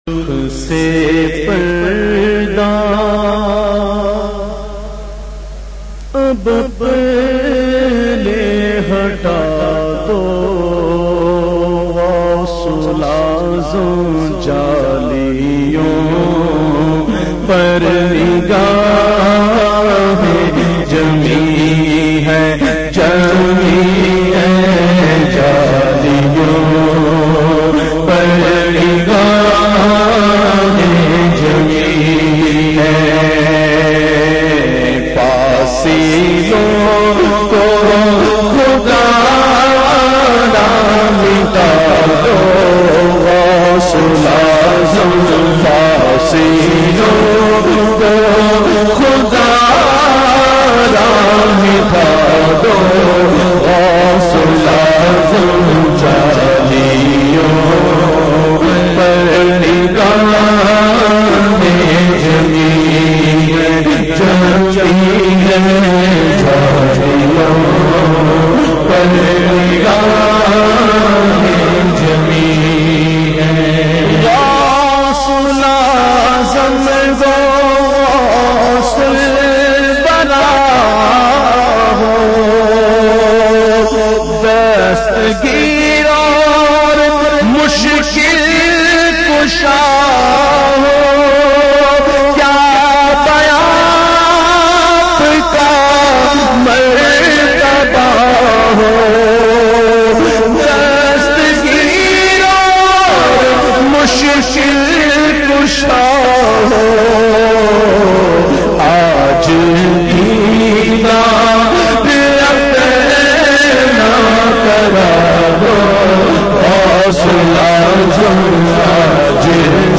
Naat MP3